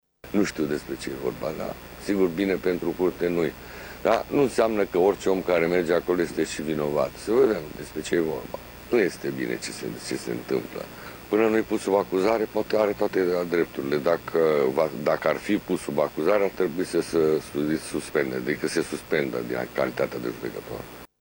Preşedintele CCR, Augustin Zegrean a declarat că nu orice om care merge la DNA este vinovat, dar că imaginea Curţii este afectată: